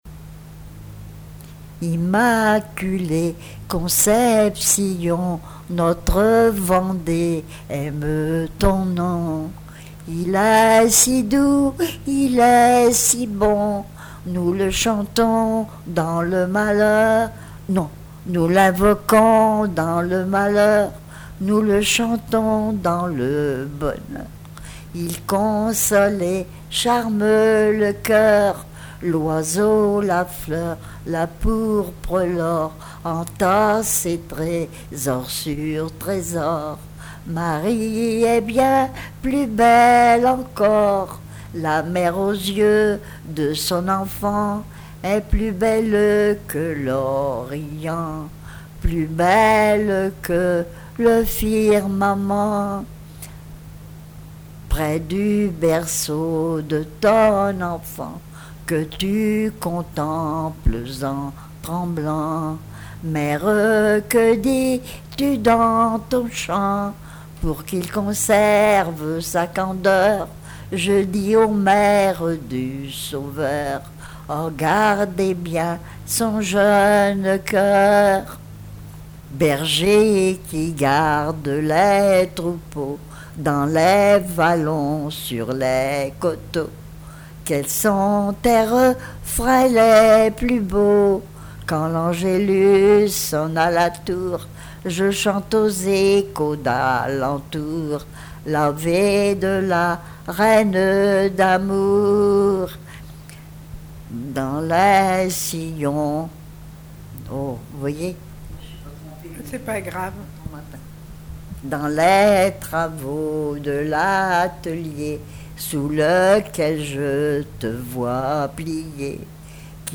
témoignages sur le vécu de l'interviewée
Pièce musicale inédite